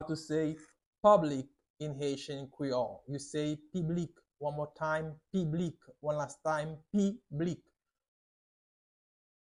Pronunciation:
21.How-to-say-Public-in-Haitian-Creole-–-Piblik-with-pronunciation.mp3